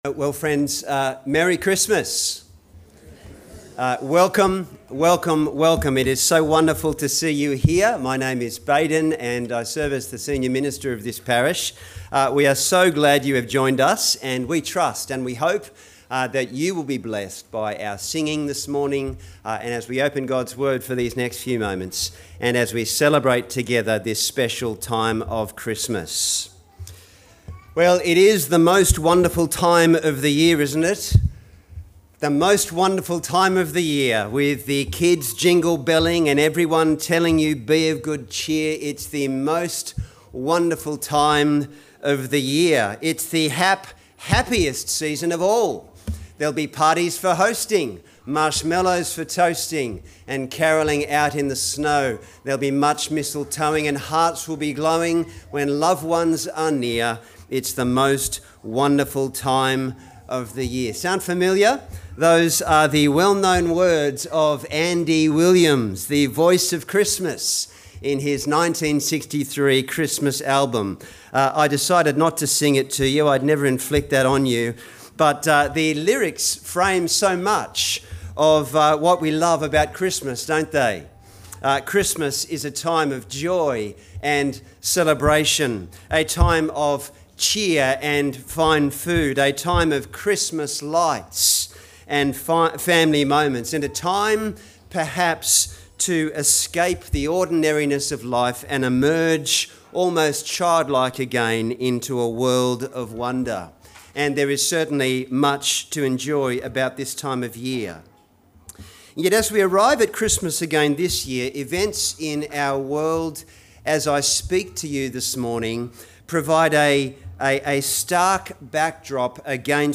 Sermon: Majesty in the Manger – 10am